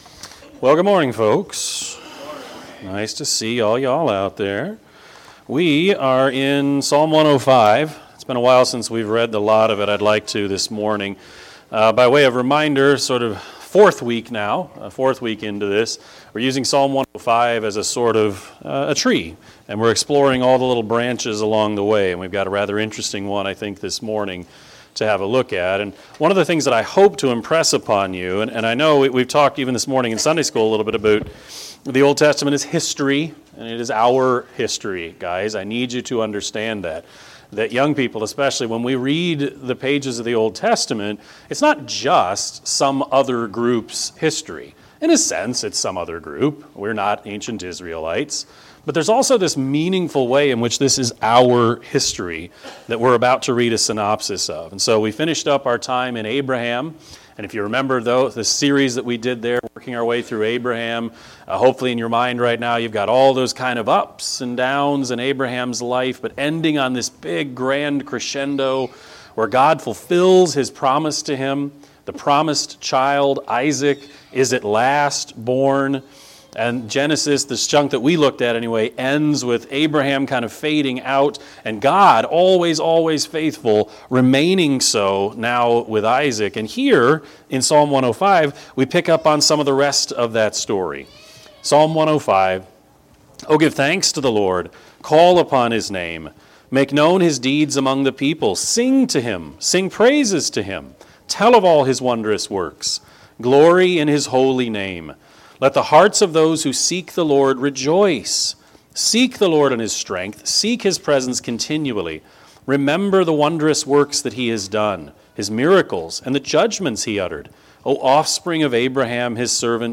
Sermon-5-15-22-Edit.mp3